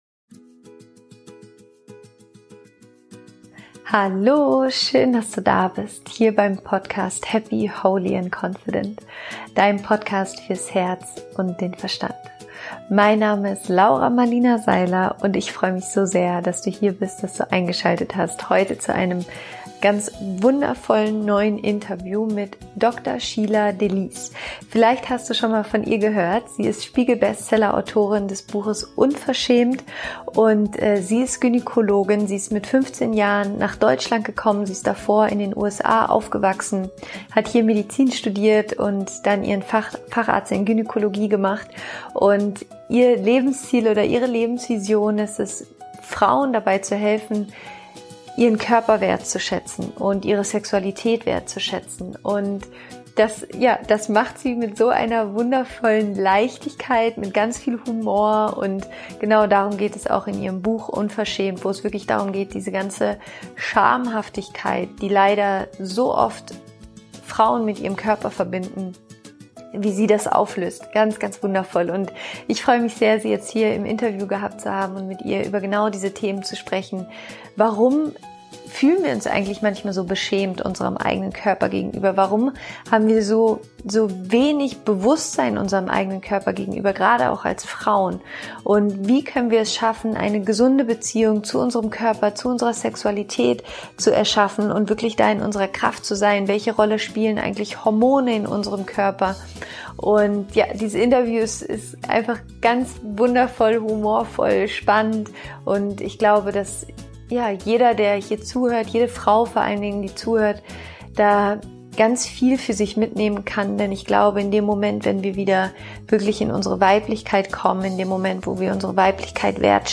Wie du dich als Frau in deinem Körper zu Hause fühlen kannst - Interview Special